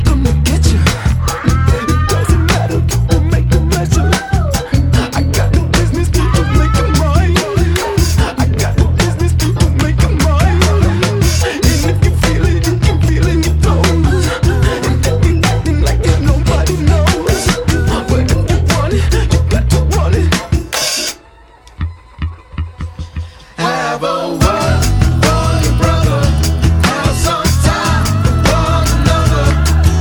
R&B Soul